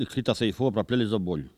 Mémoires et Patrimoines vivants - RaddO est une base de données d'archives iconographiques et sonores.
Il crie pour attirer l'essaim d'abeilles